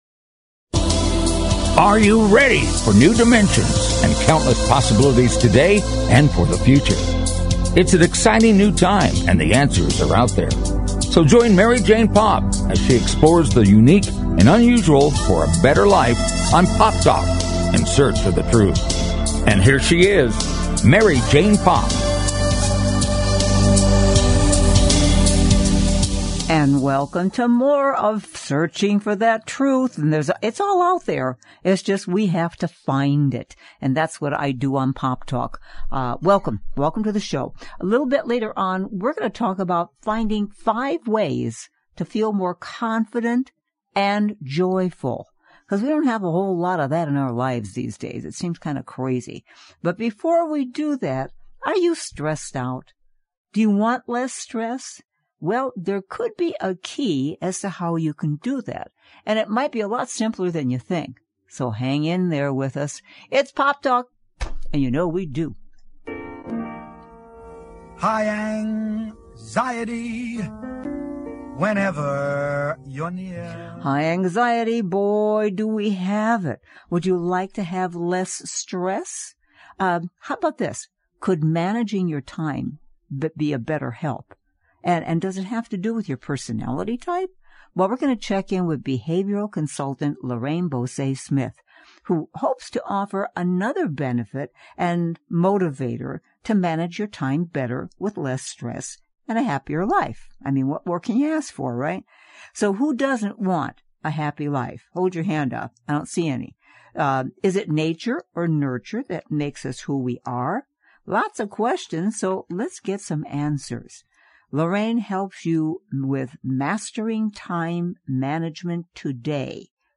A fast-paced Magazine-style Show dedicated to keeping you on the cutting edge of today's hot button issues. The show is high energy, upbeat and entertaining.